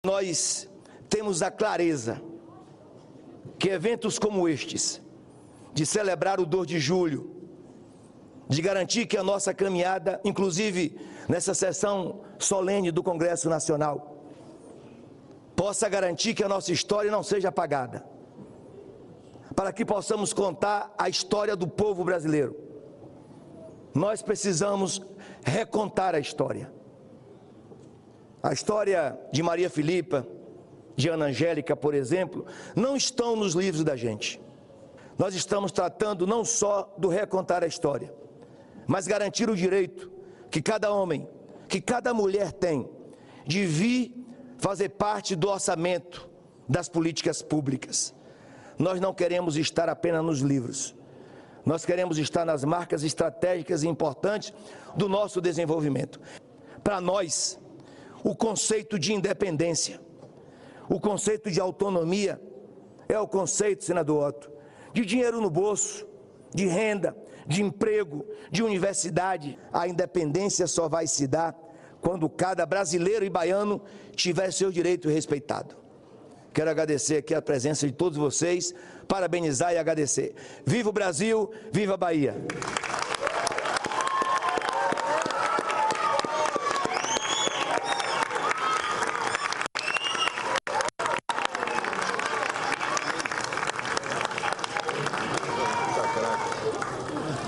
VIDEO: Governador discursa no Congresso durante sessão sobre os 200 anos da Independência do Brasil na Bahia.